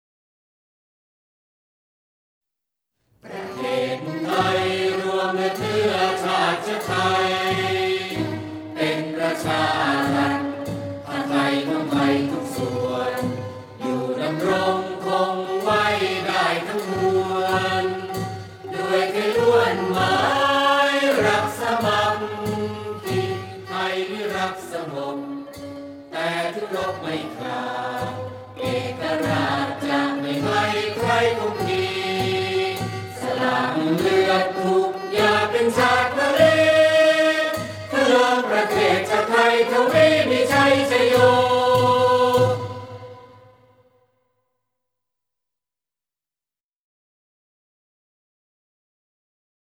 เพลงชาติ (วงปี่พาทย์-ไม้นวม)